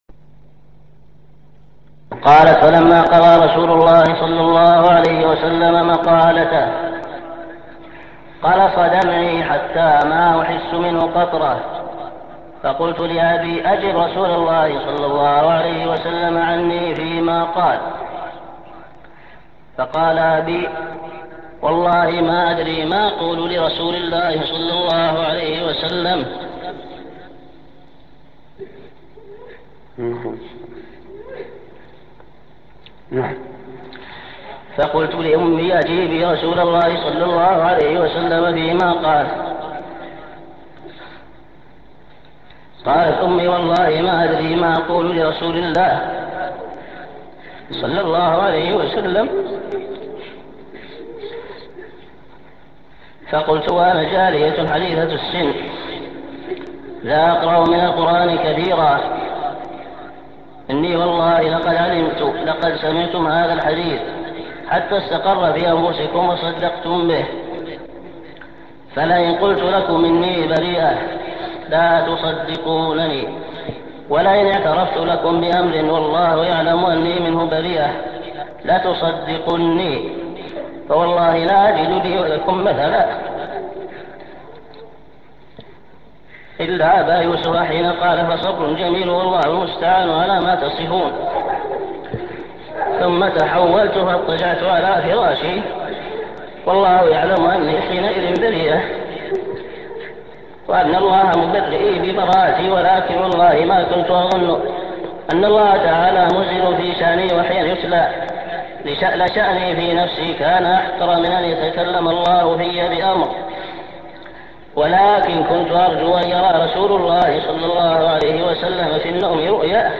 [Here is the recording of him crying over the incident of the slander of Aishah, may Allaah be pleased with her]